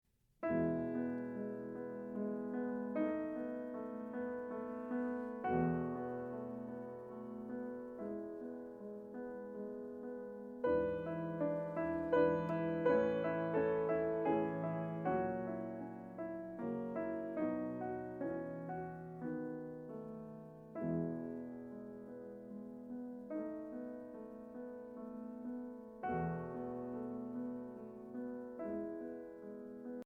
Voicing: Piano with Audio Access